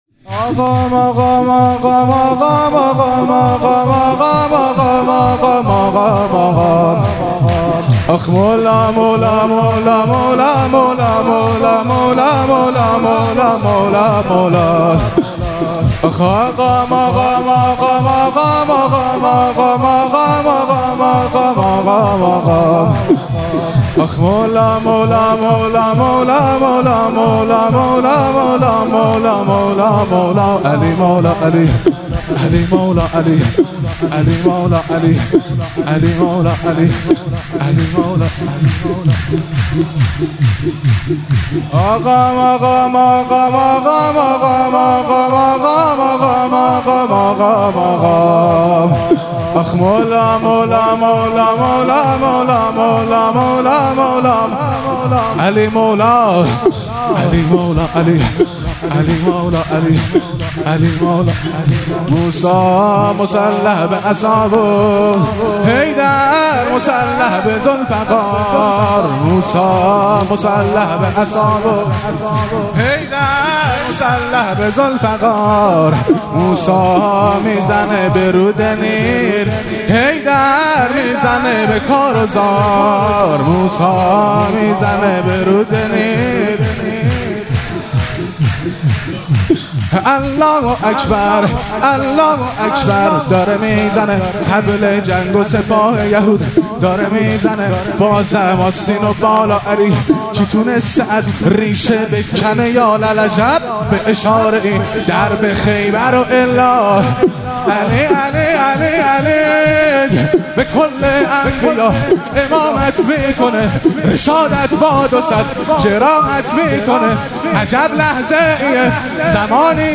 مراسم جشن شب دوم ویژه برنامه عید سعید غدیر خم 1444